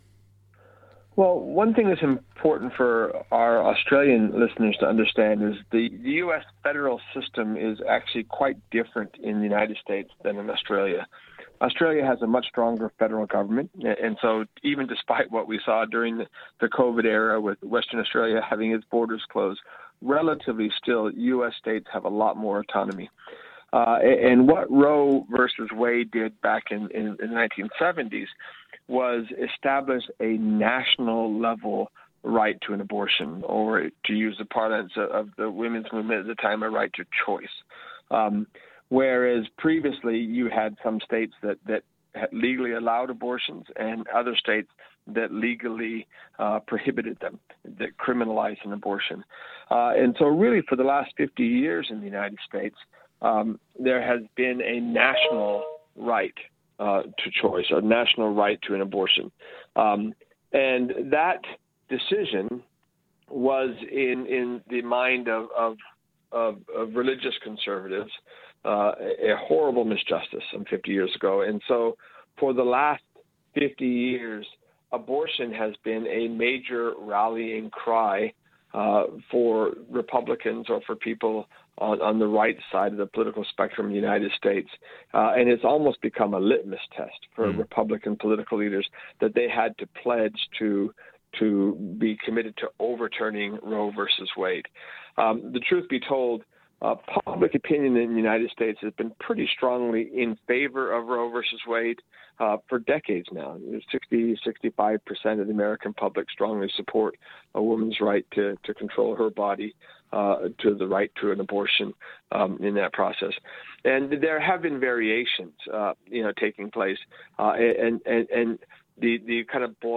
Please be aware the following interview discusses abortion, if any issues arise from this interview please contact someone you feel comfortable chatting with or contact lifeline on 13 11 14.